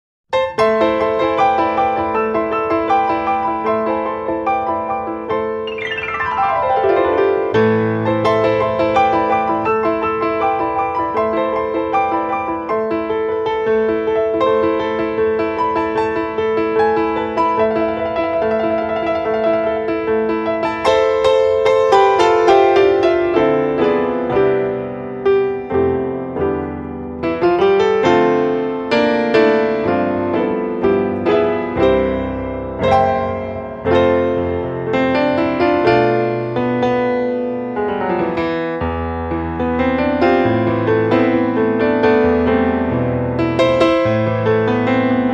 Compilation Jazz Album